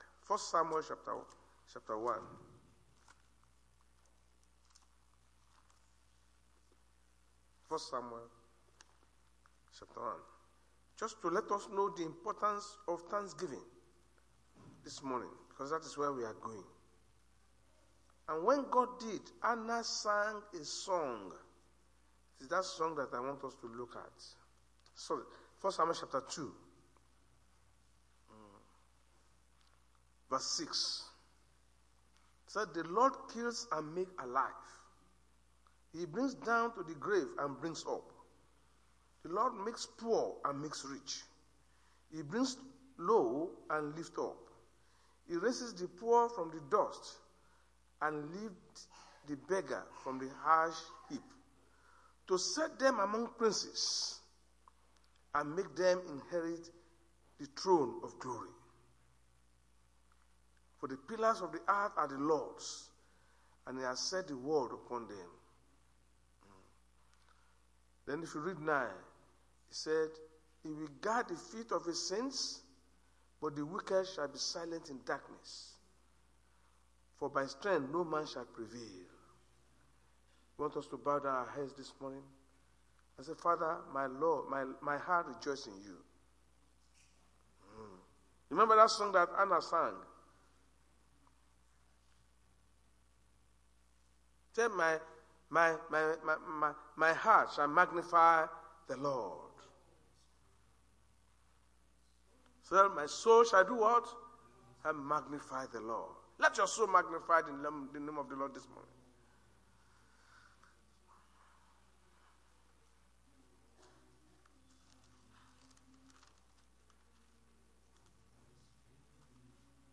RCCG House Of Glory Sunday Sermon: True Meaning Of Thanksgiving
Service Type: Sunday Church Service